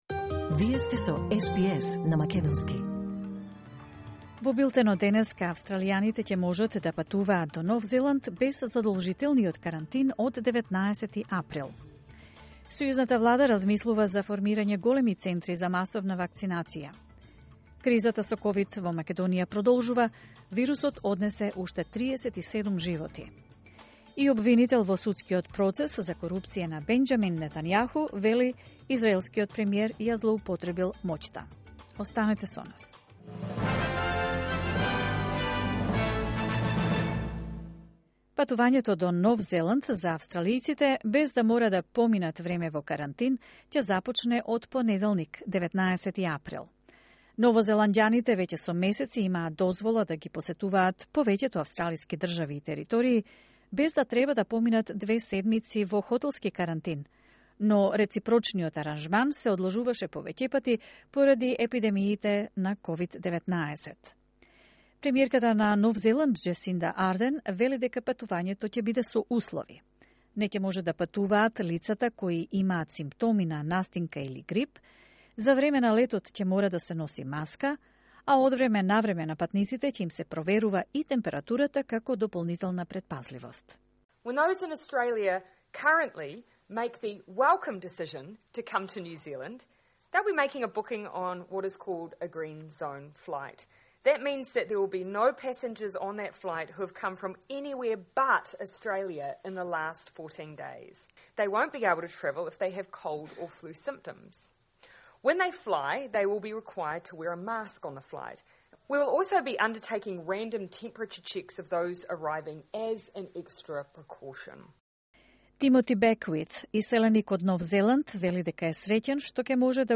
SBS News in Macedonian 6 April 2021